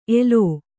♪ elō